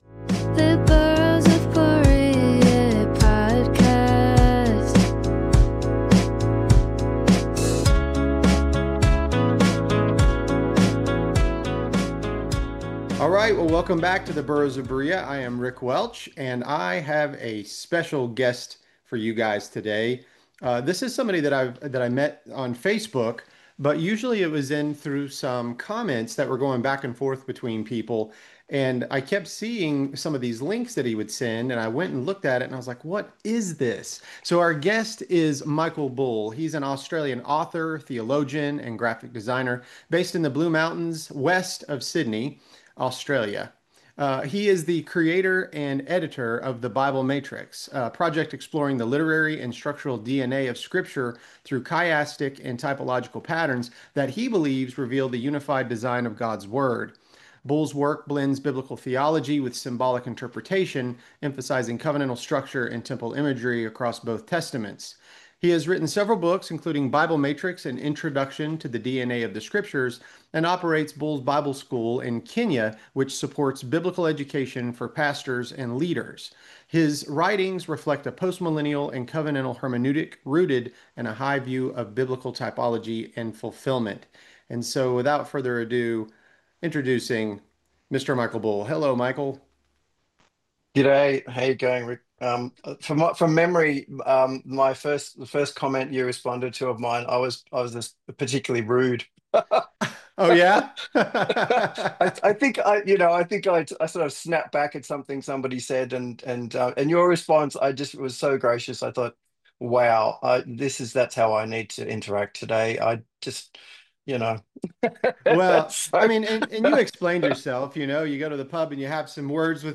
I hope you enjoy this interview as much as I did!